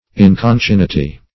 Search Result for " inconcinnity" : The Collaborative International Dictionary of English v.0.48: Inconcinnity \In`con*cin"ni*ty\, n. [L. inconcinnitas.]